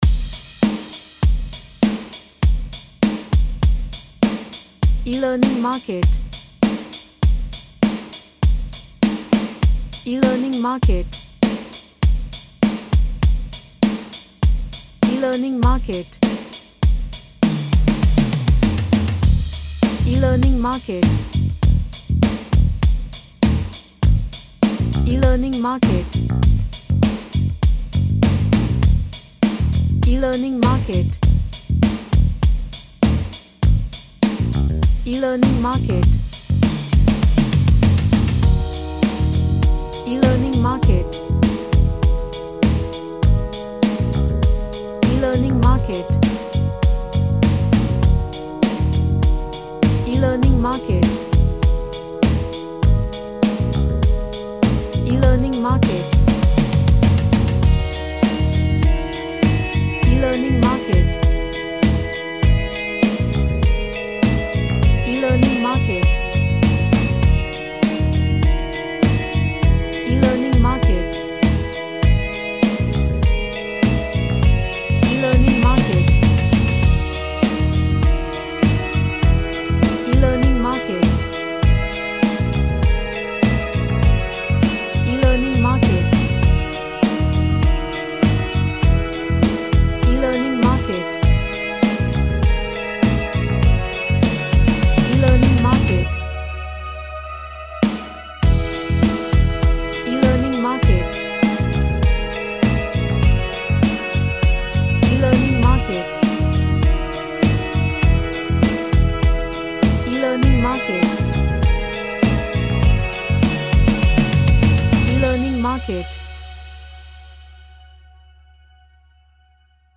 A blues track with rhodes & bass
Gentle / Light